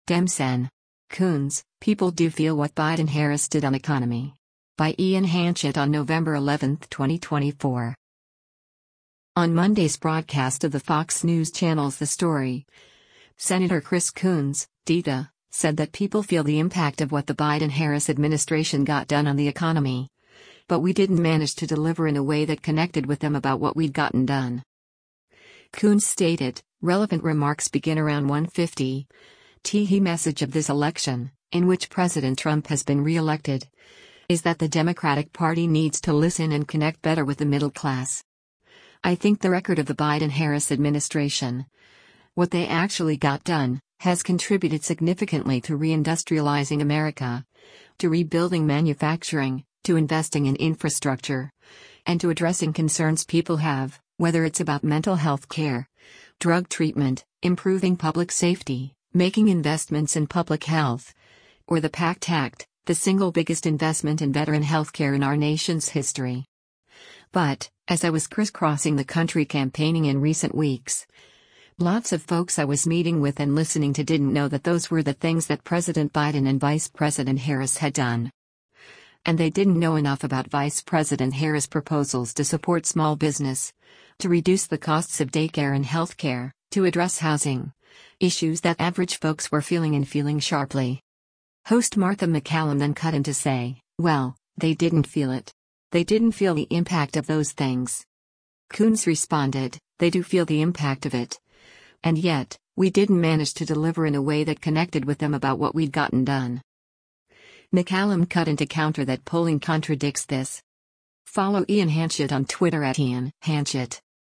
On Monday’s broadcast of the Fox News Channel’s “The Story,” Sen. Chris Coons (D-DE) said that people “feel the impact” of what the Biden-Harris administration got done on the economy, but “we didn’t manage to deliver in a way that connected with them about what we’d gotten done.”
Host Martha MacCallum then cut in to say, “Well, they didn’t feel it. They didn’t feel the impact of those things.”
MacCallum cut in to counter that polling contradicts this.